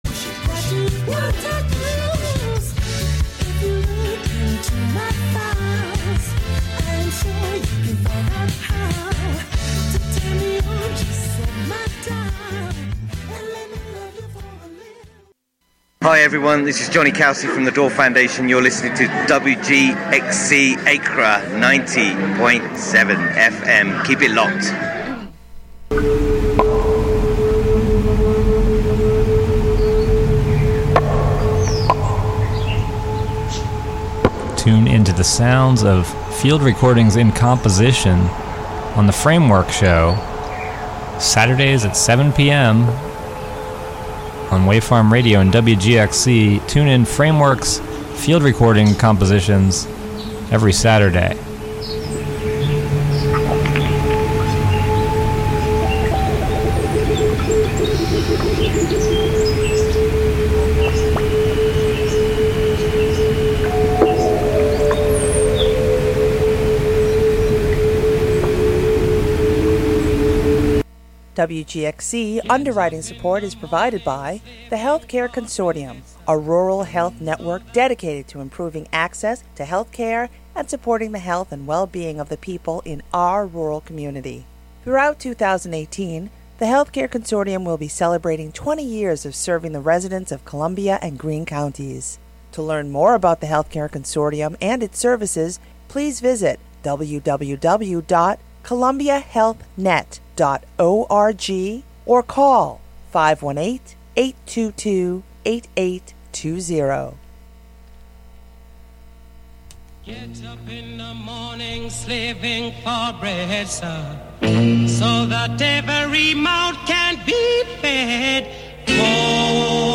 12pm Monthly program featuring music and interviews.